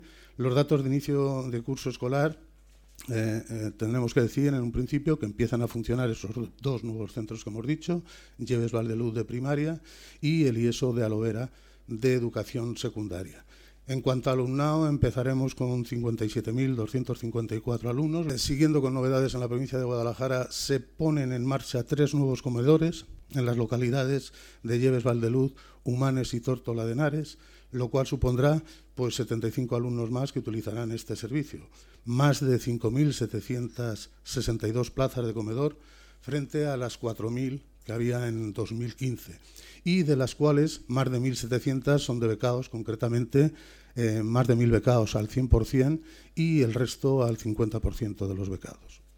El director provincial de Educación, Cultura y Deportes, Faustino Lozano, habla sobre la apertura de nuevos centros educativos en la provincia de Guadalajara en el nuevo curso escolar.